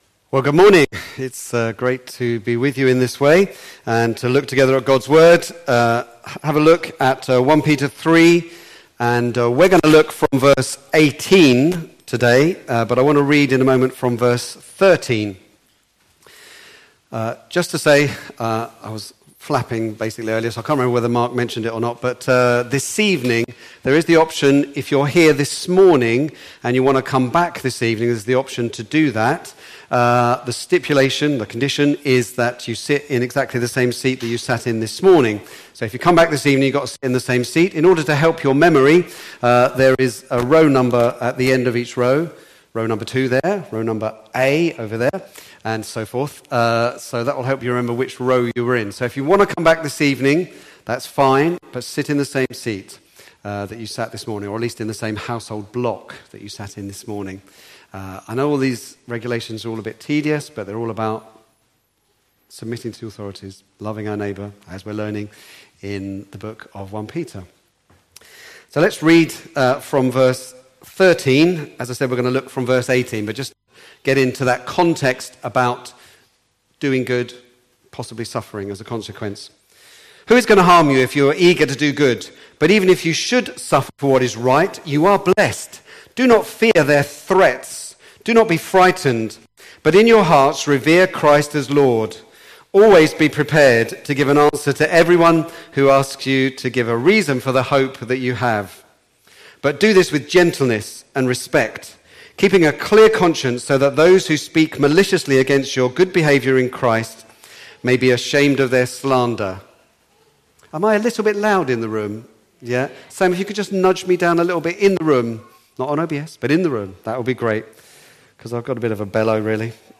Back to Sermons Ultimate Victory